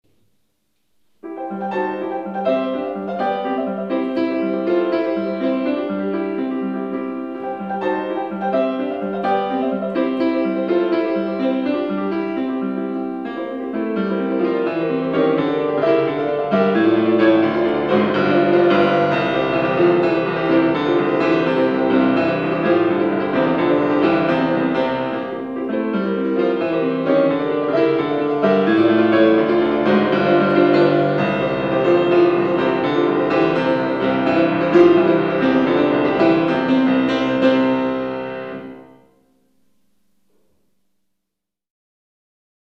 Here's some of my piano recordings.